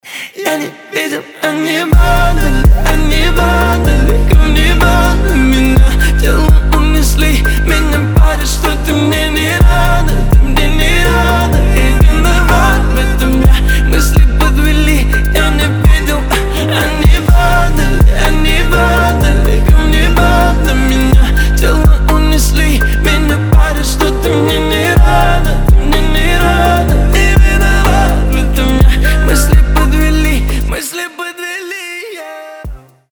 • Качество: 320, Stereo
лирика
красивый мужской голос